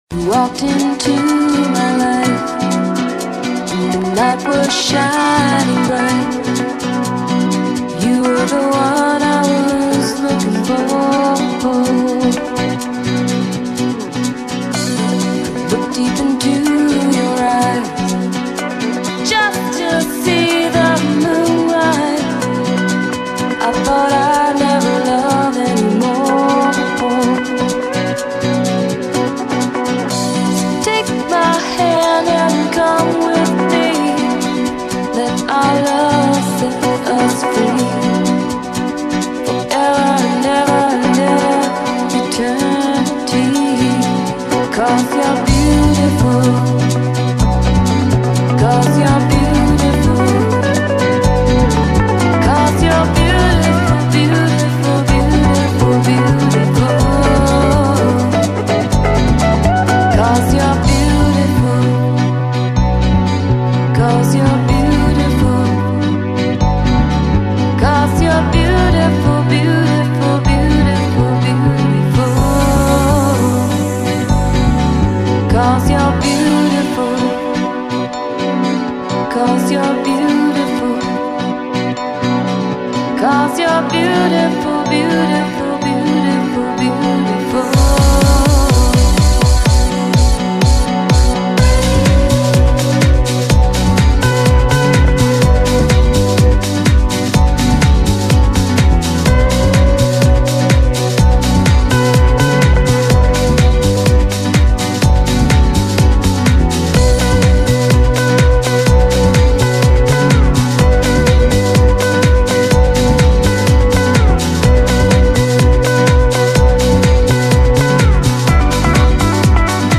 甜美中氤蕴迷幻，舒适解压的聆听旅程。
Bossa Nova，Downtempo，Nu Jazz 与 Lounge